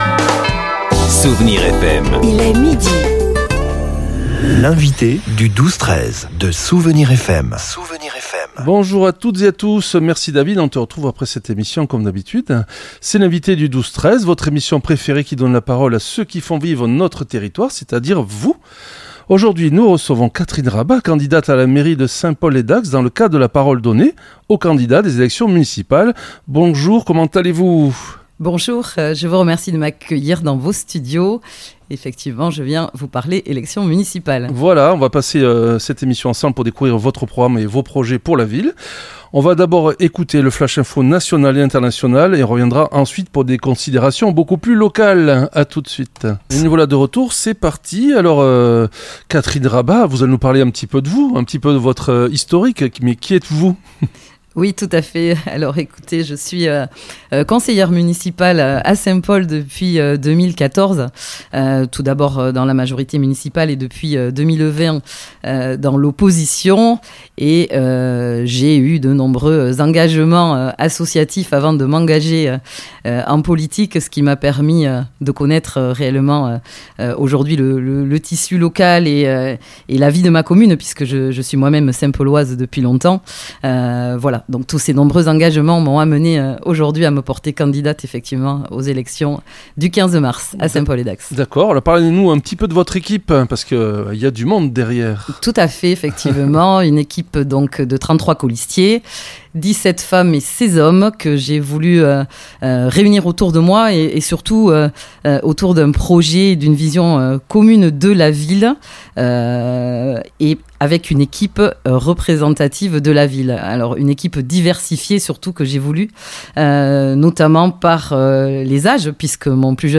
L’invité(e) du 12-13 recevait aujourd’hui Catherine Raba, conseillère municipale et vice-présidente du Grand Dax, candidate à la mairie de Saint-Paul-lès-Dax.
L’entretien a également balayé sept projets structurants pour le quotidien des Saint-Paulois.